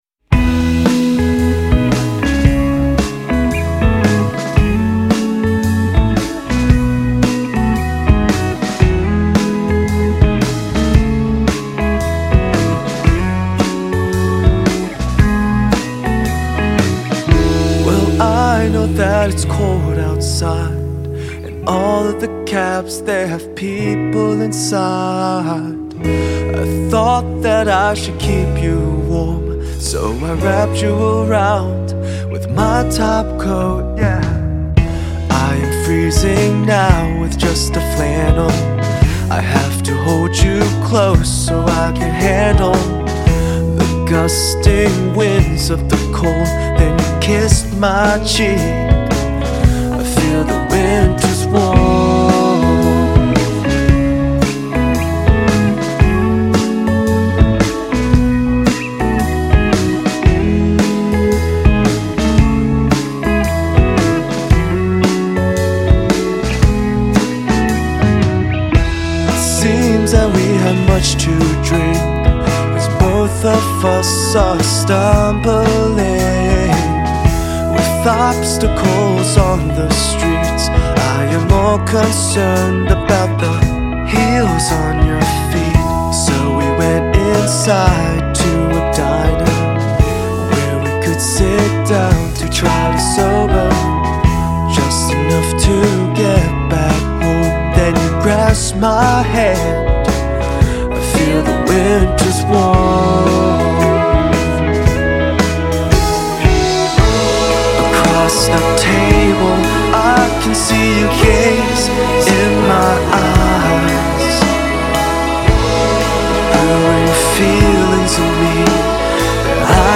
indie pop/rock band